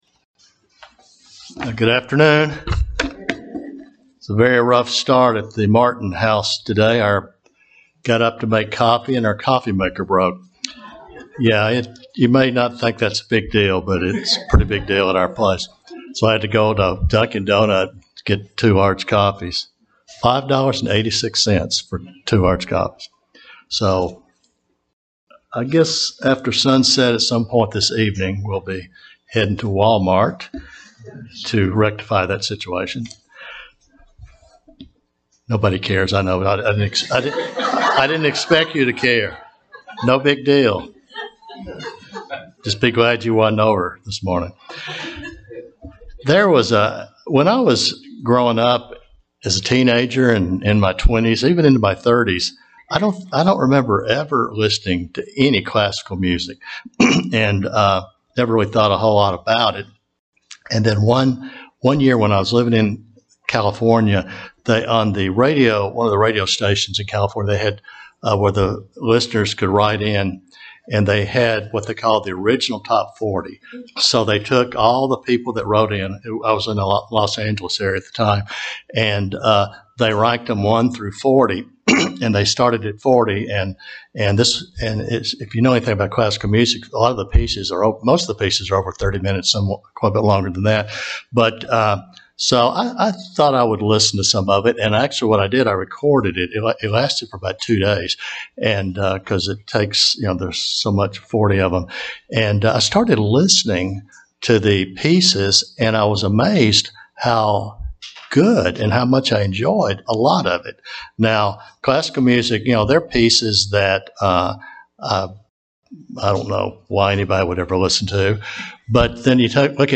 One way of describing the life of a Christian is to compare it to the four seasons of the year: spring, summer, fall, and winter. We will look at the spring and summer seasons of a Christian in this sermon
Given in Huntsville, AL